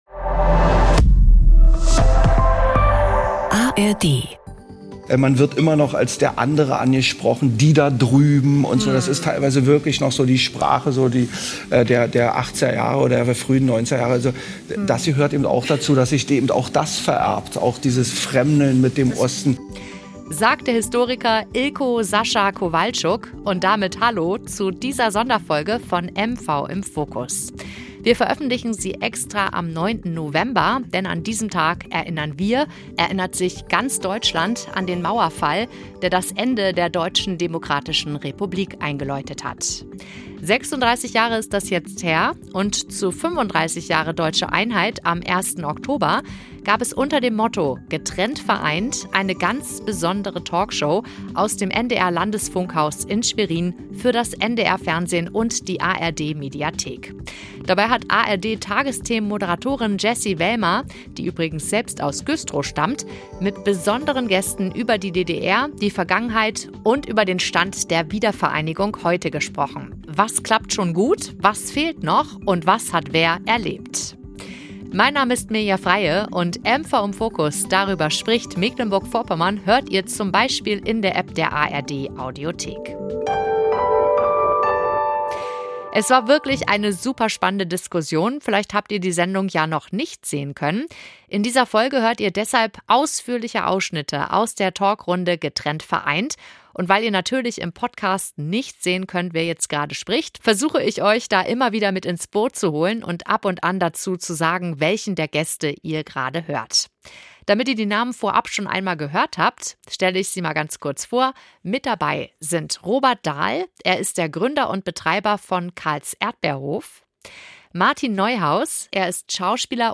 Zu 35 Jahre Deutsche Einheit am 1. Oktober gab es unter dem Motto „Getrennt vereint“ eine ganz besondere Talkshow aus dem Schweriner NDR Landesfunkhaus im NDR Fernsehen: Dabei hat Tagesthemen Moderatorin Jessy Wellmer, die übrigens selbst aus Güstrow stammt, mit spannenden Gästen über die DDR, die Vergangenheit und über den Stand der Wiedervereinigung heute gesprochen. Was klappt schon gut?